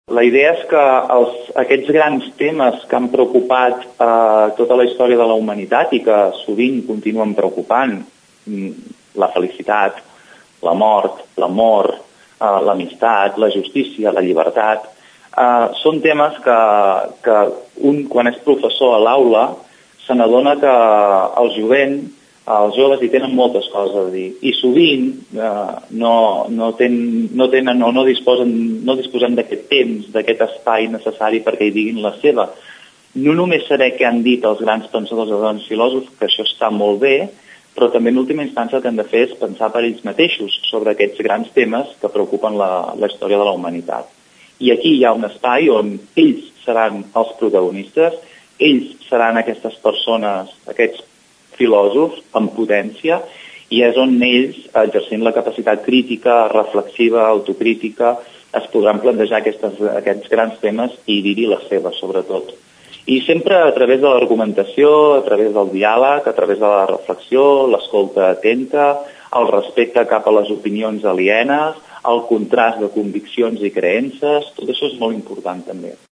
Ho explica en declaracions a Ràdio Tordera